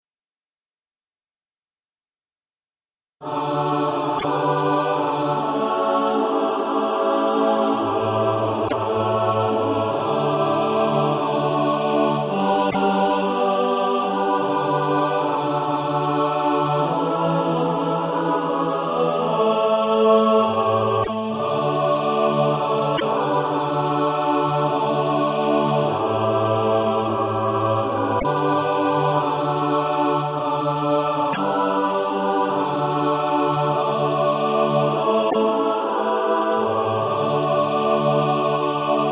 And also a mixed track to practice to
(SATB) Author
Practice then with the Chord quietly in the background.